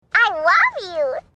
ted i love you Meme Sound Effect